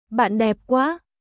バン デップ クア！🔊